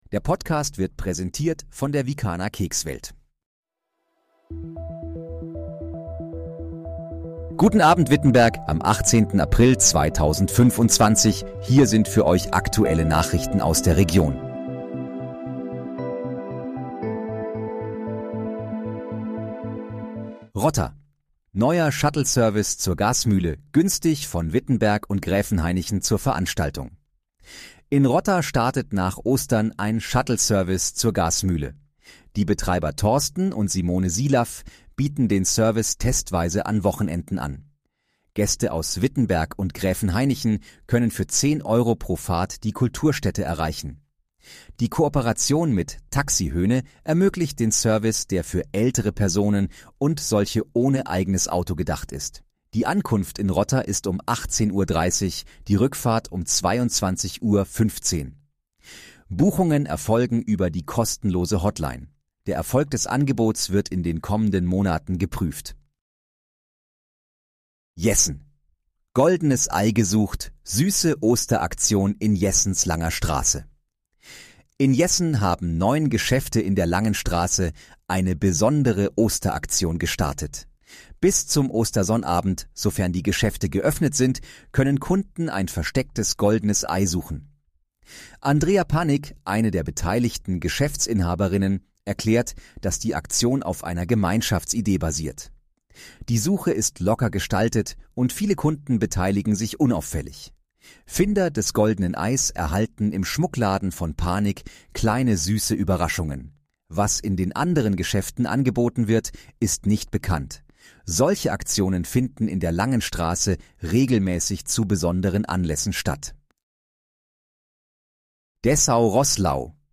Guten Abend, Wittenberg: Aktuelle Nachrichten vom 18.04.2025, erstellt mit KI-Unterstützung
Nachrichten